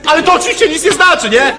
Worms speechbanks
ohdear.wav